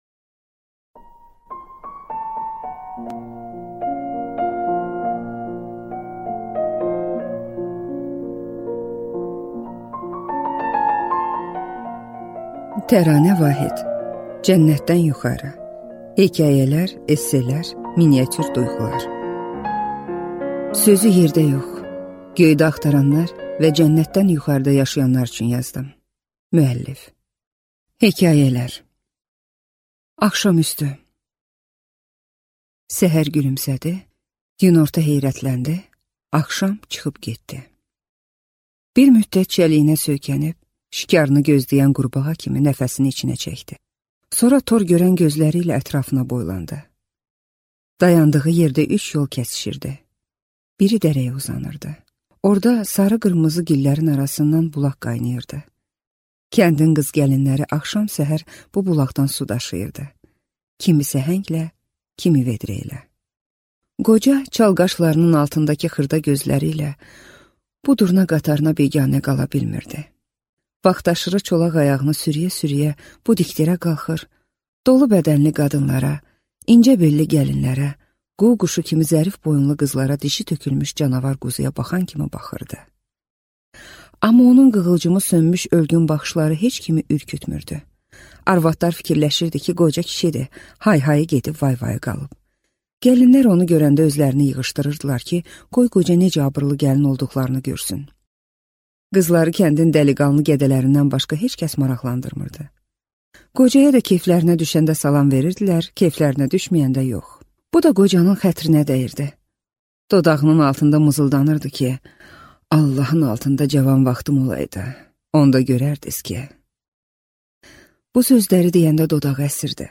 Аудиокнига Cənnətdən yuxarı | Библиотека аудиокниг